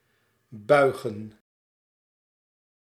Ääntäminen
IPA: /bʌj.ɣǝ:/